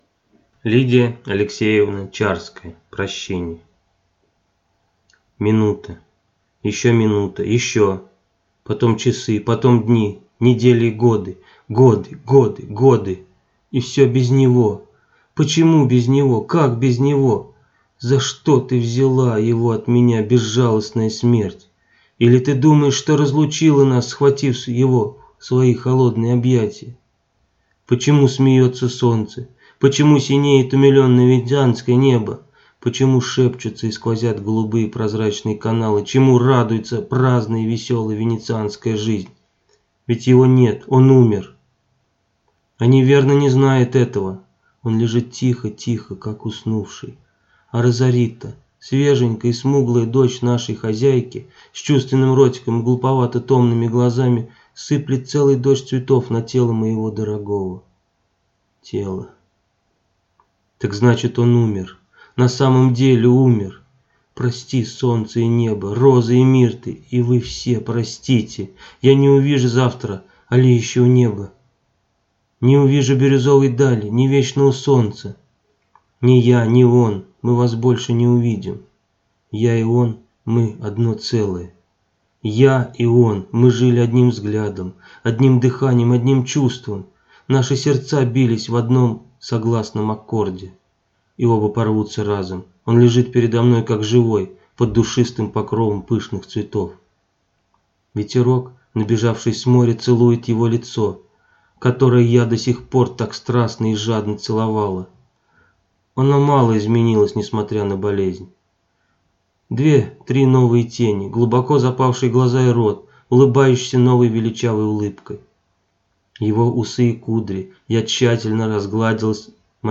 Аудиокнига Прощение | Библиотека аудиокниг